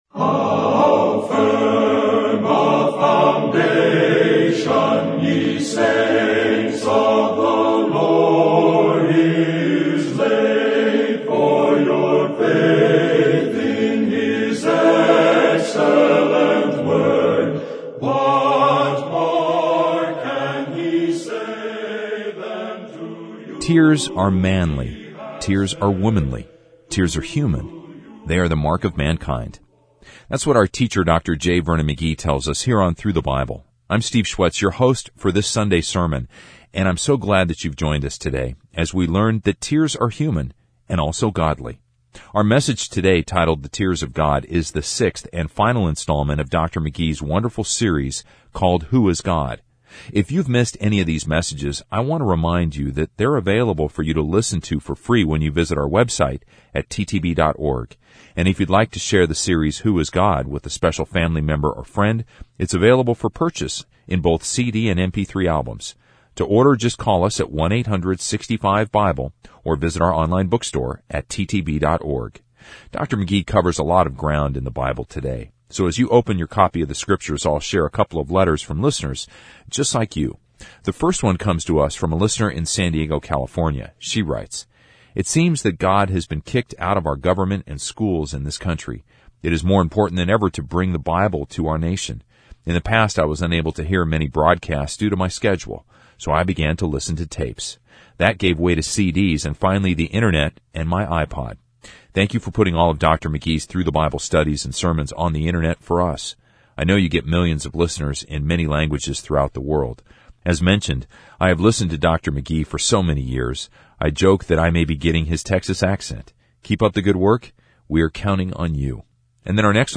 TTBR_Sermon_2232_The_Tears_of_God.mp3